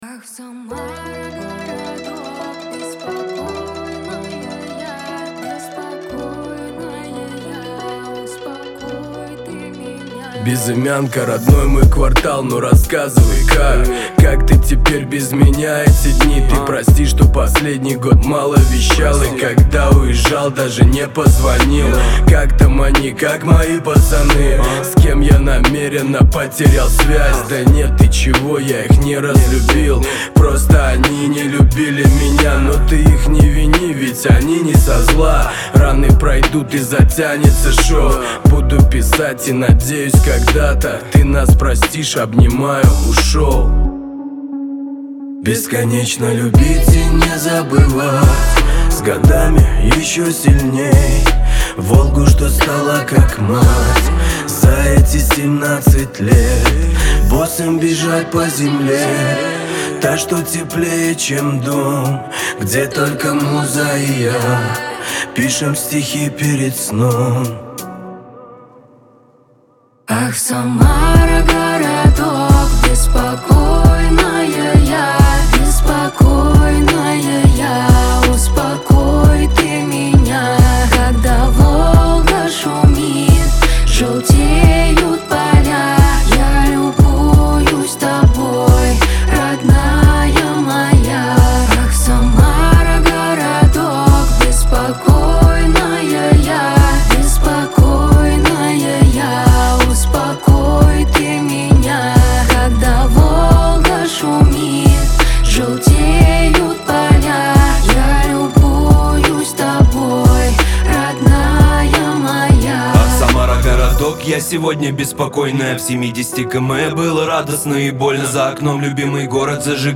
Жанр: rusrap